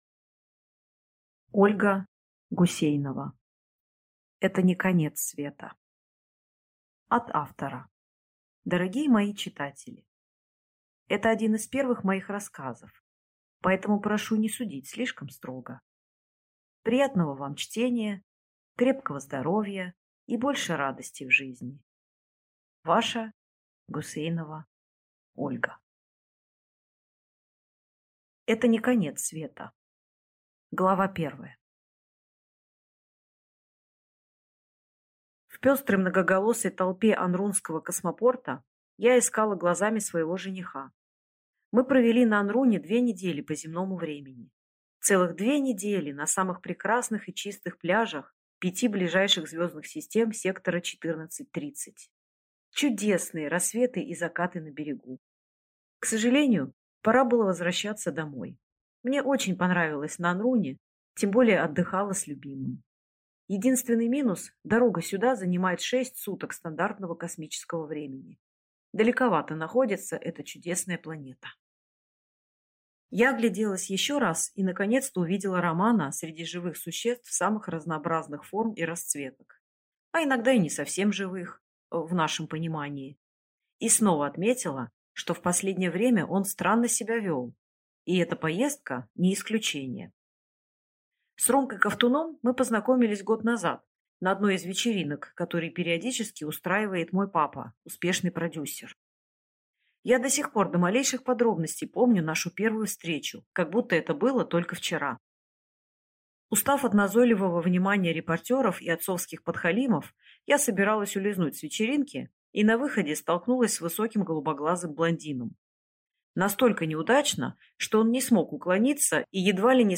Аудиокнига Это не конец света | Библиотека аудиокниг
Прослушать и бесплатно скачать фрагмент аудиокниги